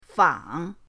fang3.mp3